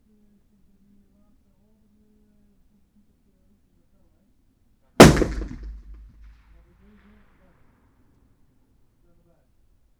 Environmental
Streetsounds
UrbanSoundsNew / 01_gunshot /shot556_72_ch01_180718_163438_38_.wav